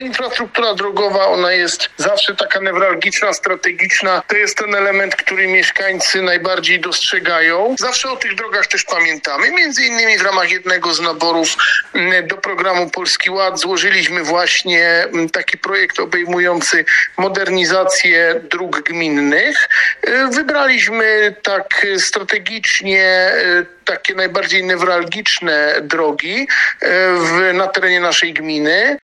Mówi burmistrz gminy Kęty Krzysztof Jan Klęczar.